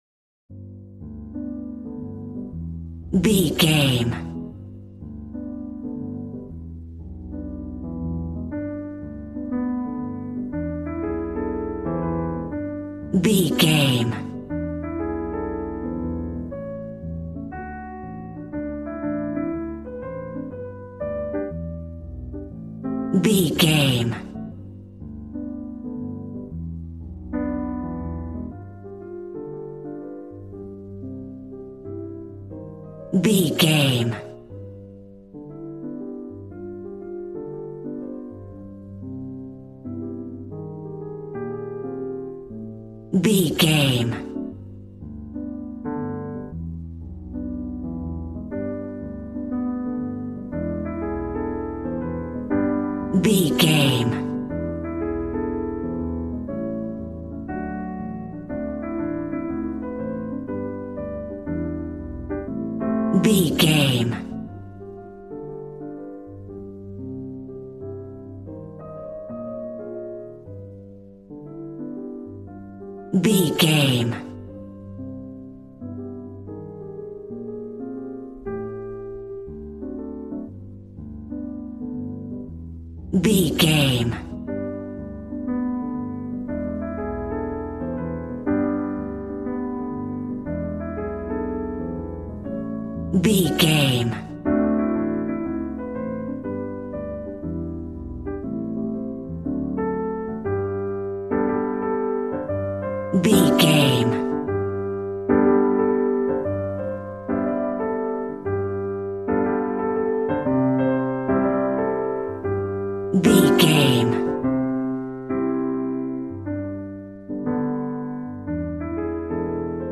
Smooth jazz piano mixed with jazz bass and cool jazz drums.,
Ionian/Major
smooth
piano
drums